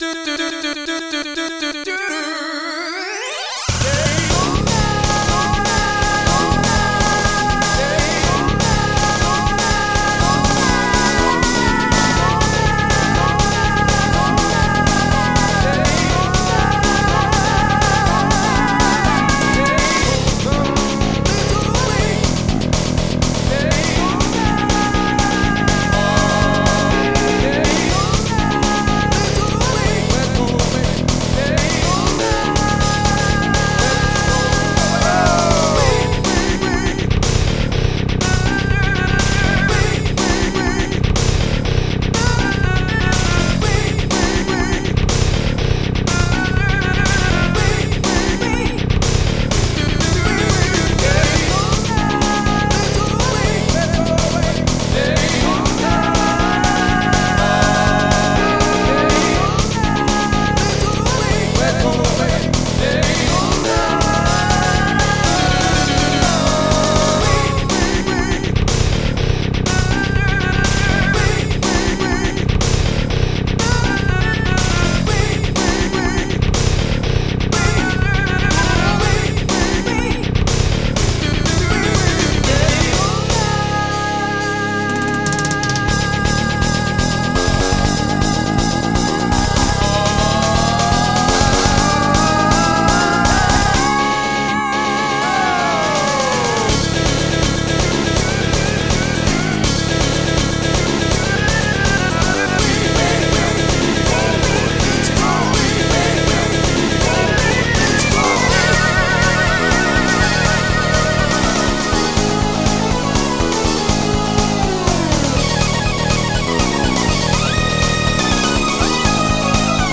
A very silly cover
made using samples ripped from the game